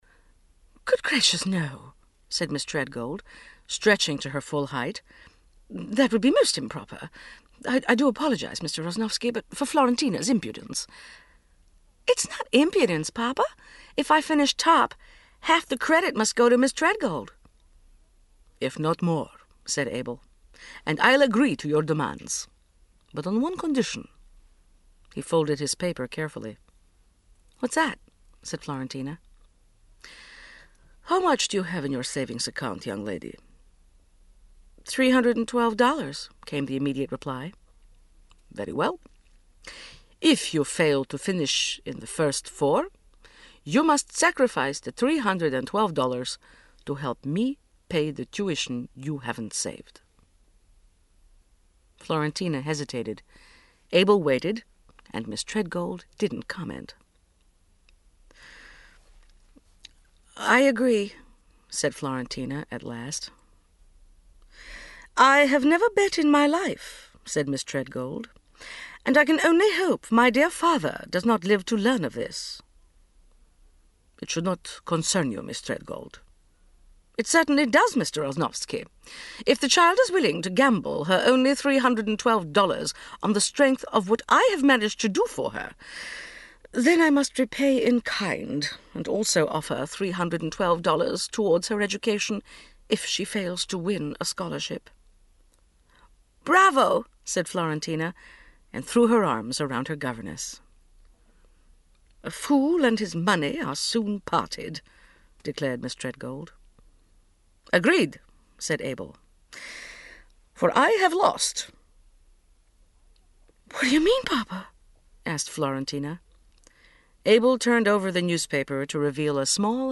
37serv Audiobooks/Jeffrey Archer - Prodigal Daughter (1982) (96)